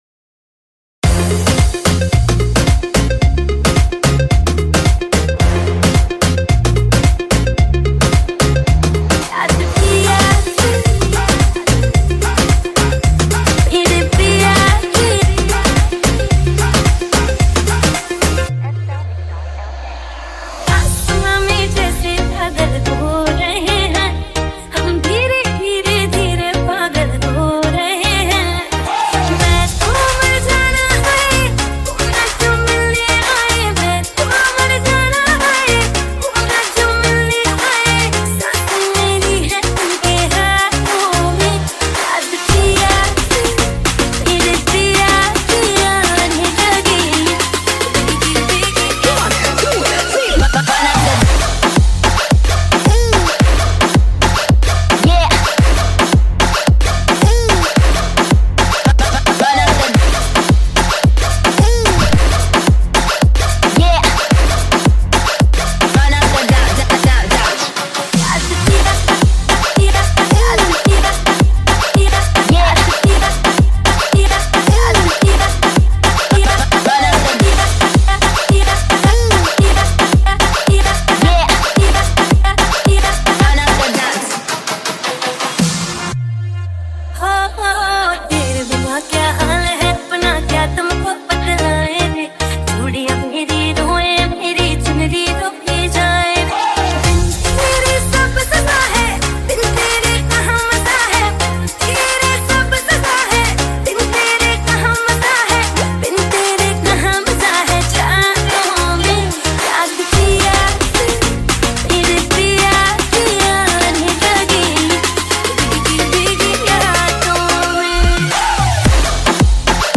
Guitars